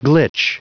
Prononciation du mot glitch en anglais (fichier audio)
Prononciation du mot : glitch